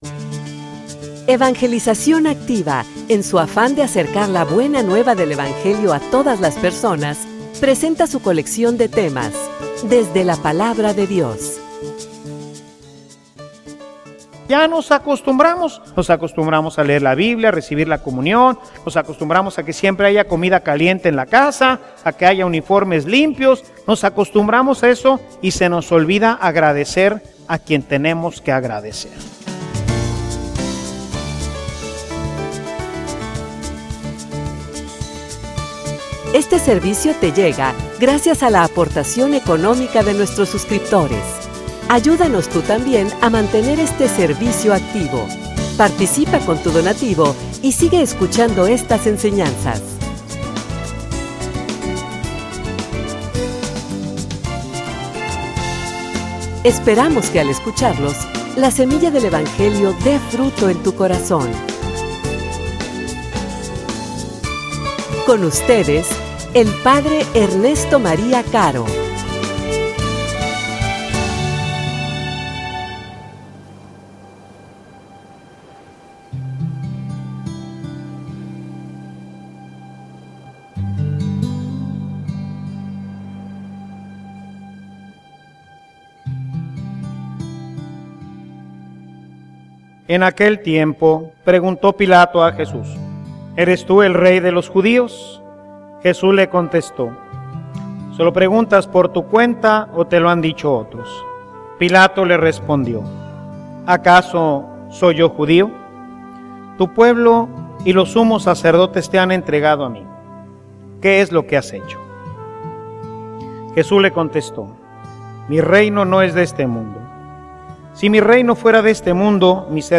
homilia_Solo_tu_eres_digno.mp3